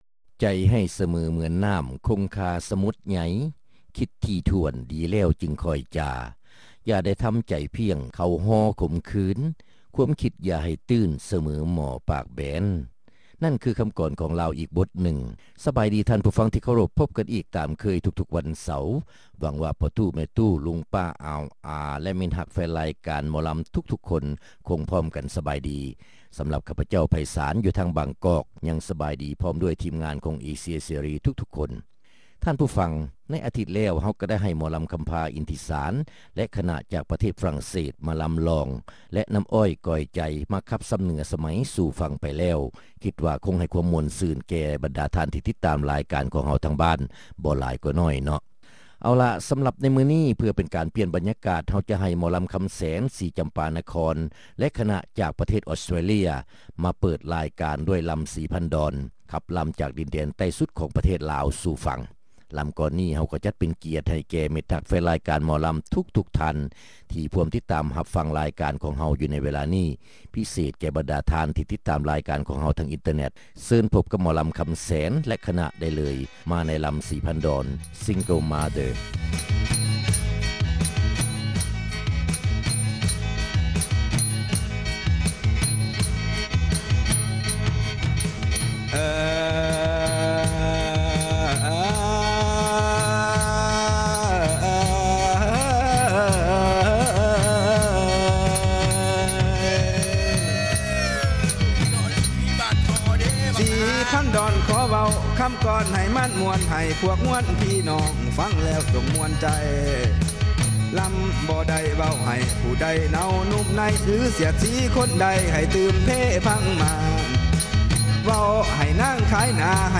ຣາຍການໜໍລຳ ປະຈຳສັປະດາ ວັນທີ 9 ເດືອນ ກັນຍາ ປີ 2005